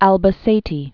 (ălbə-sātē, älvä-thĕtĕ)